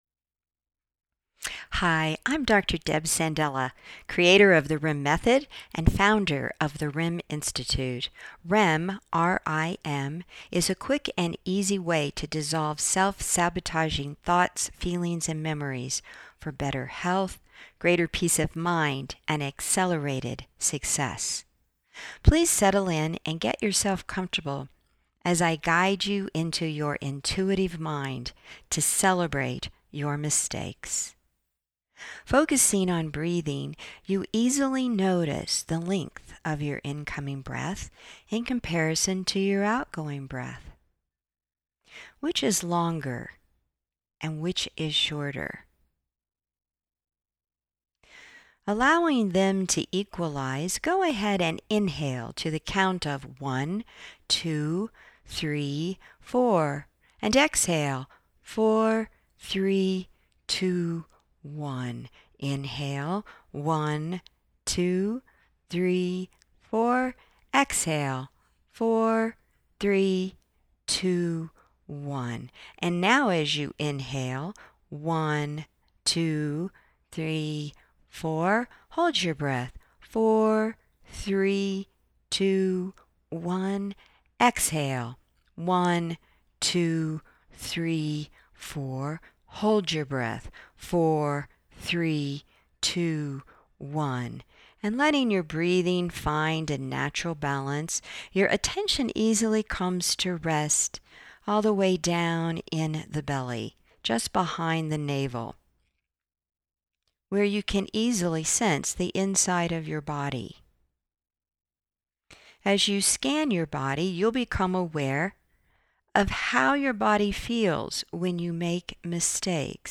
April Meditation